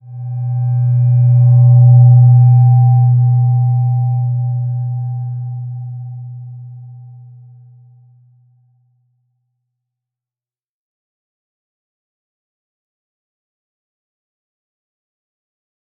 Slow-Distant-Chime-B2-f.wav